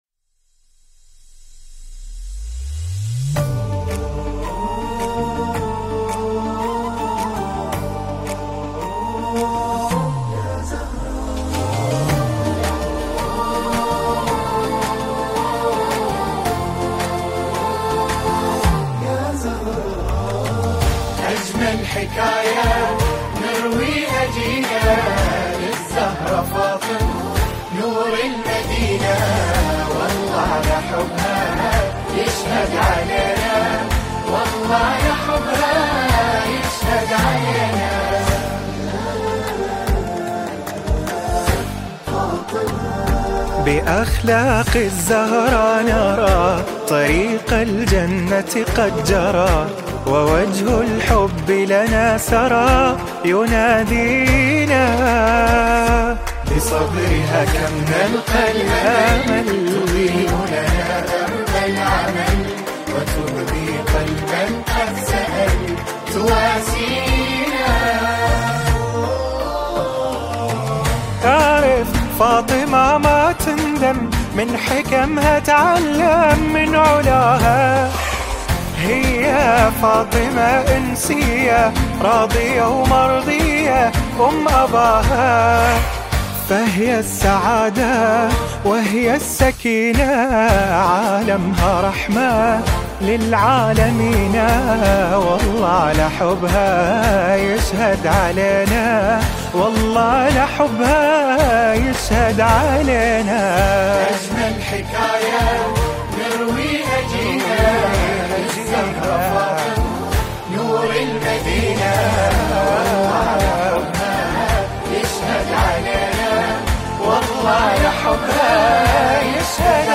ویژه جشن ولادت حضرت فاطمه سلام الله علیها